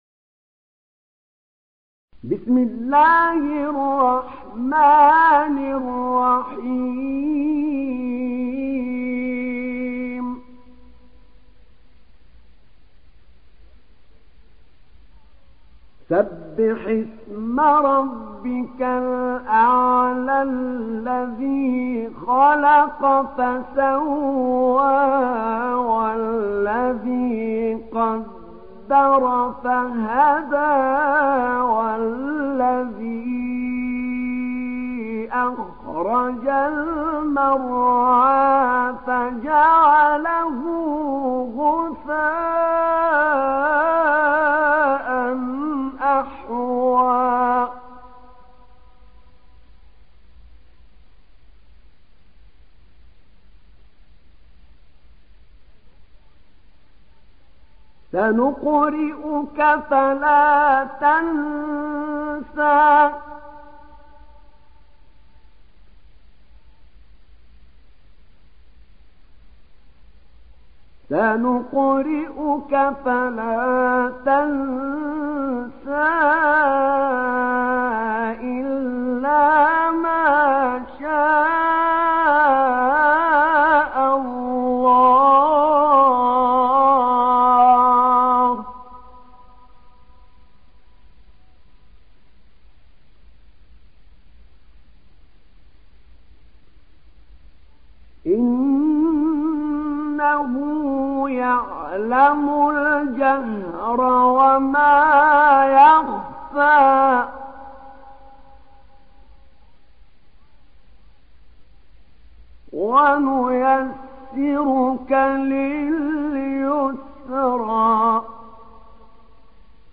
دانلود سوره الأعلى mp3 أحمد نعينع (روایت حفص)
دانلود سوره الأعلى أحمد نعينع